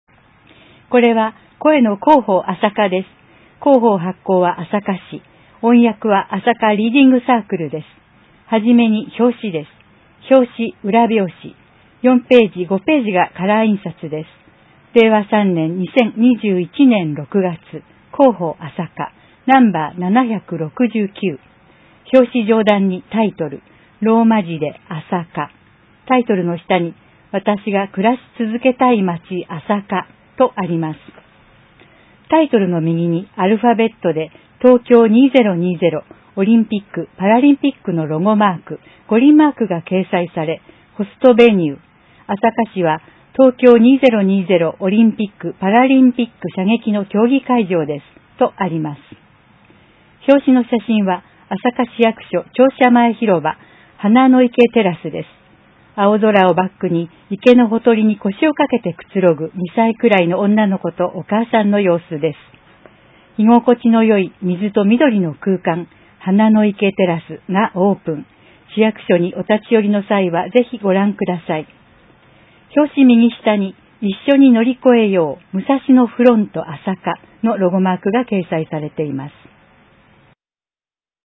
｢声の広報あさか」は、市内のボランティア「朝霞リーディングサークル」のご協力で、視覚に障害がある方のご自宅にＣＤ（デイジー形式）を郵送しています。